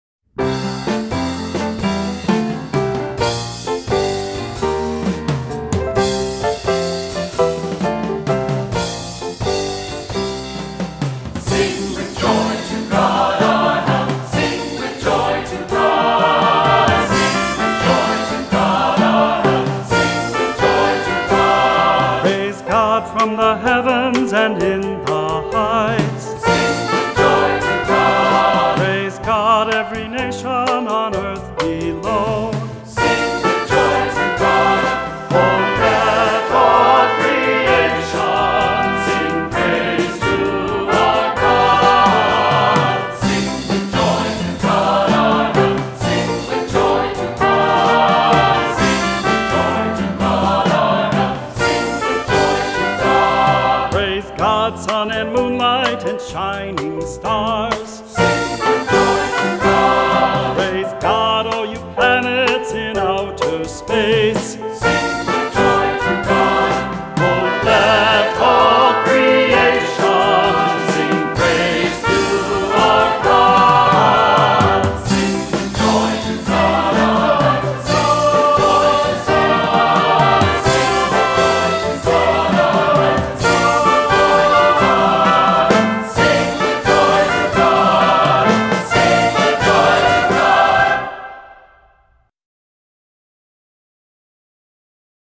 Voicing: SATB, descant, cantor, assembly